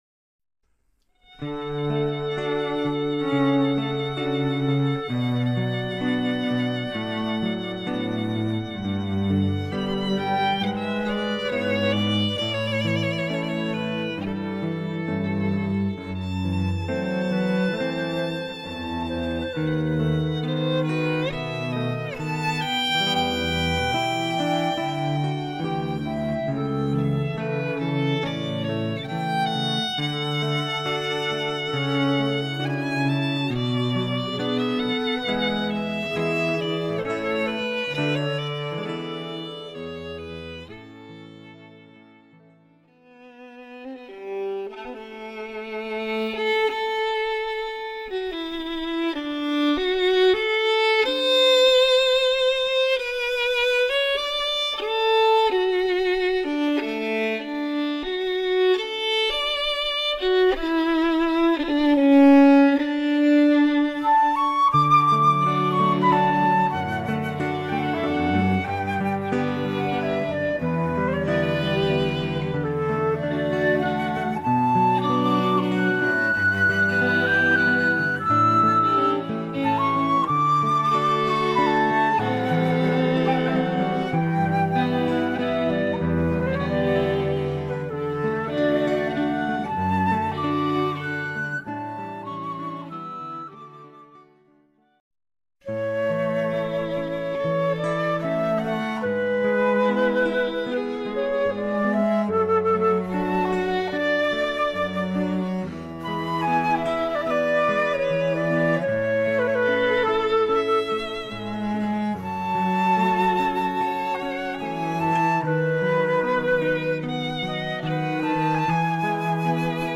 —Ceremony—
String & Flute Quartet